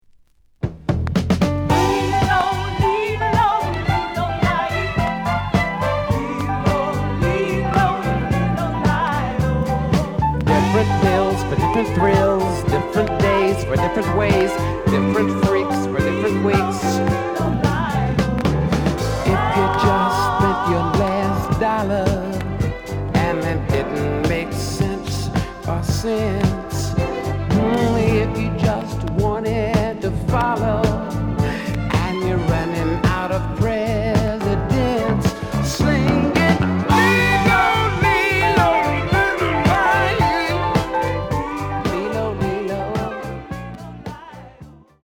The listen sample is recorded from the actual item.
●Genre: Funk, 70's Funk
Some click noise on A side due to scratches.